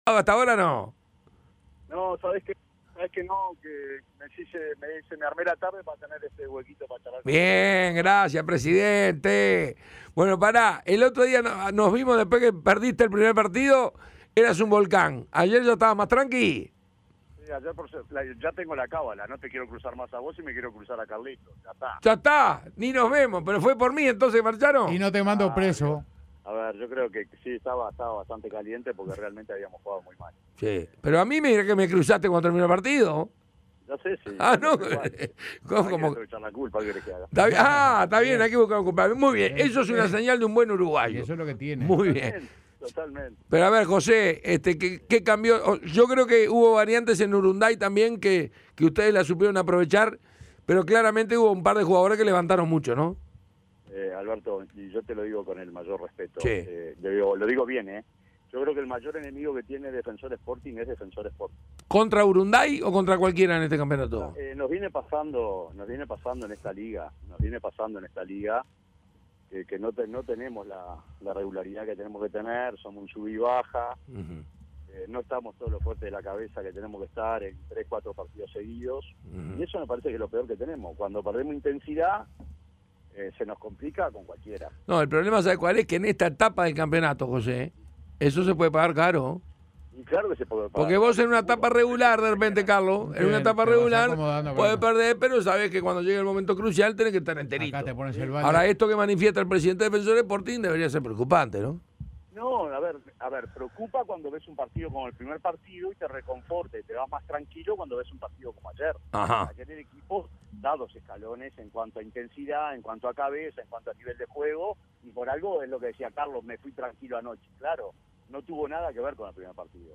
Se mostró feliz por el triunfo de su equipo pero aseguró que hay cosas para mejorar. Entrevista completa.